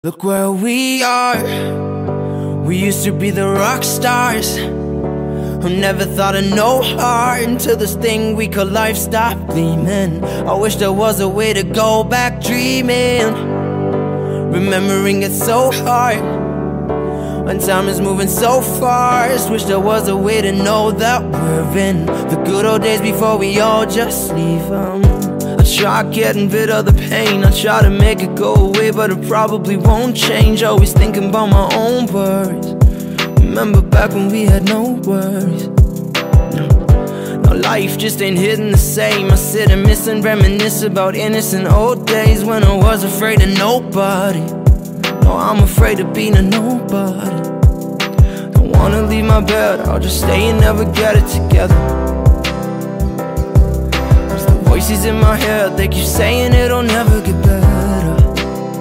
Kategorien: POP